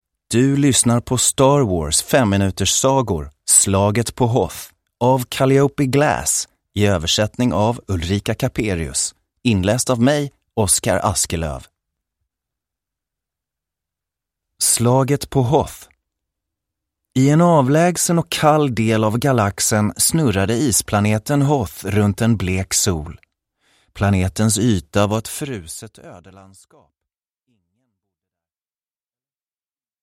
Högt tempo utlovas i dessa fem minuter långa ljudboksberättelser.